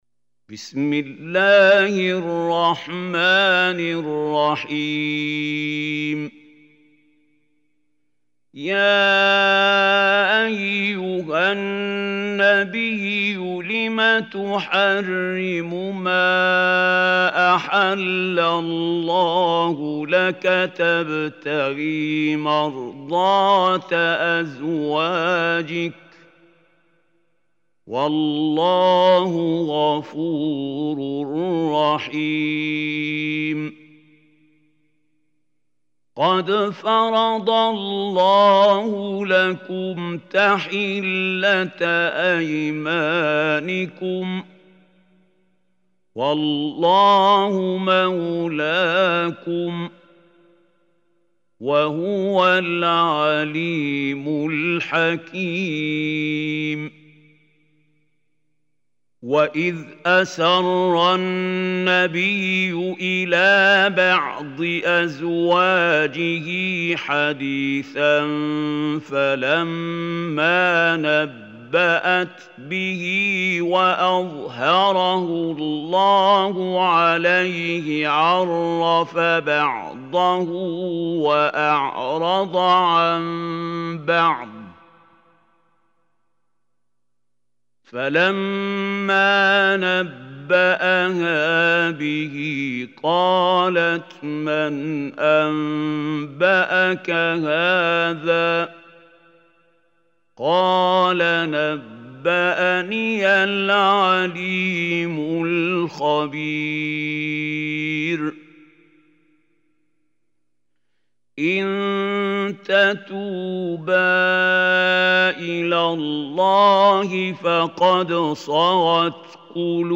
Surah Tahrim Recitation by Sheikh Khalil Hussary
Surah Tahrim is 66 surah of Holy Quran. Listen or play online mp3 tilawat / recitation in Arabic in the beautiful voice of Sheikh Mahmoud Khalil Al Hussary.